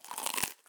hunger_ng_eat.2.ogg